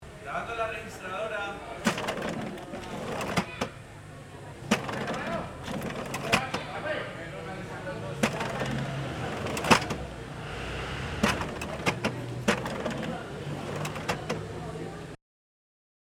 Máquina registradora, Trujillo
16. Maquina registradora.mp3
Registro sonoro del proceso de producción de café en Trujillo, Valle del Cauca. Caja registradora del pago del café.